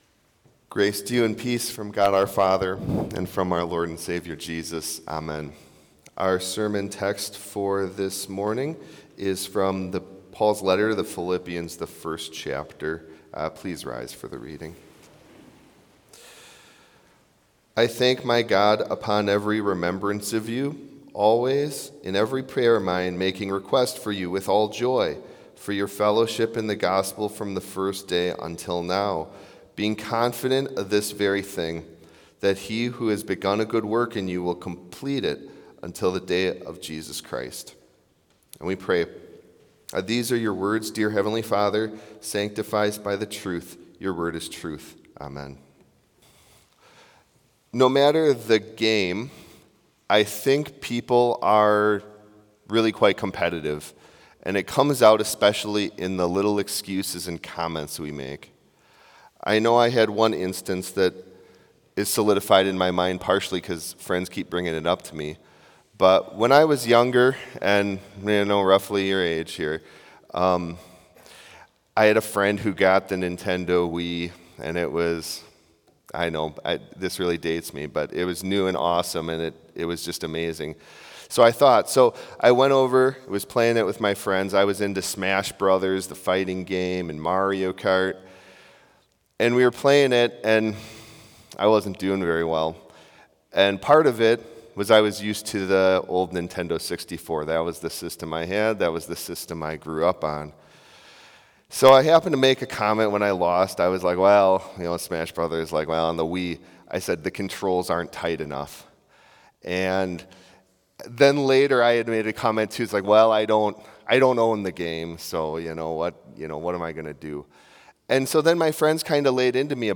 Complete service audio for Chapel - Thursday, August 28, 2025